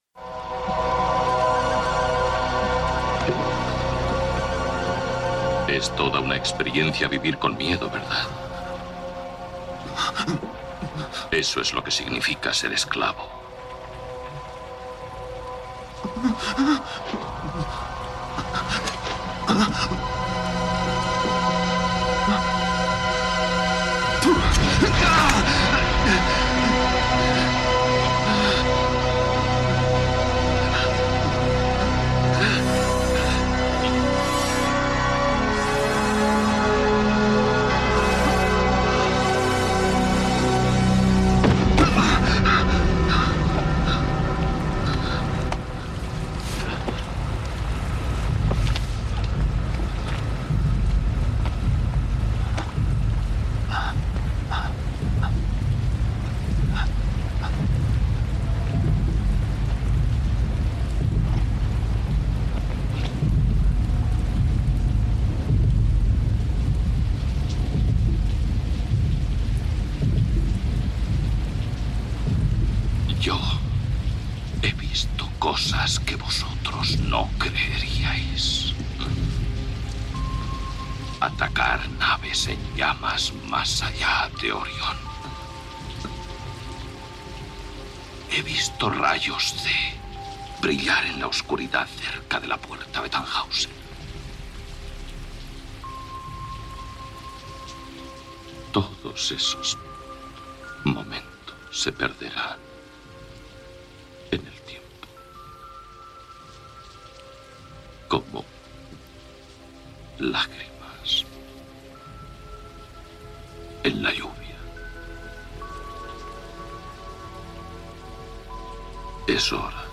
0823ddfd756adbe5b3a5f2c2a0e7ff5ba207928b.mp3 Títol Ràdio Granollers Emissora Ràdio Granollers Titularitat Pública municipal Nom programa Audiovisual MAC Descripció Primera edició del programa. Fragment de la pel·lícula "Blade runner", careta del programa, comentari sobre la intel·ligència artificial, sumari del programa, notícies d'actualitat relacionades amb l'audiovisual Gènere radiofònic Divulgació